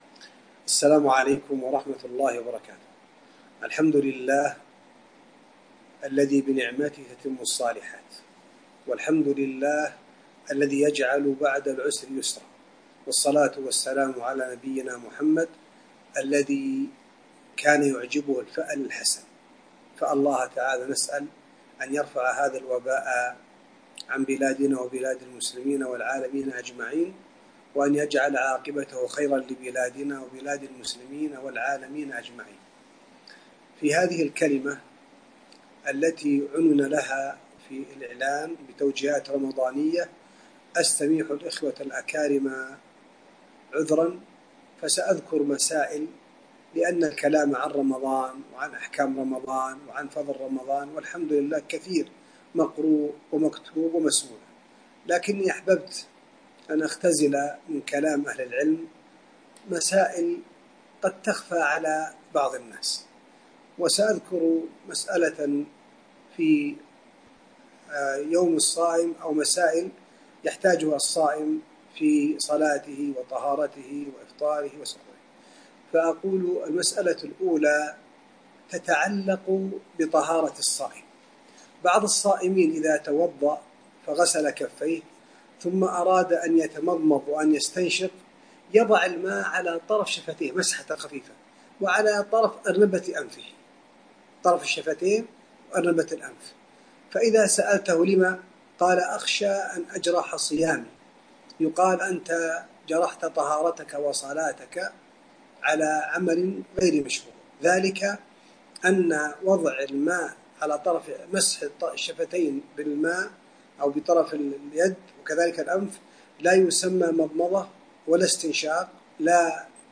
كلمة - فضل شهر رمضان عبر البث المباشر 1441 هــ